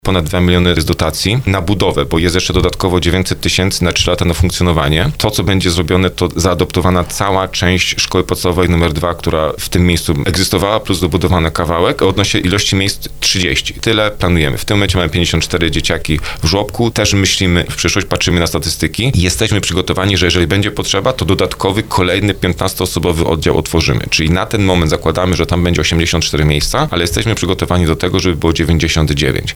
Jak mówi wójt Marcin Kiwior, na ten cel zostaną zaadaptowane pomieszczenia po dawnej szkole podstawowej.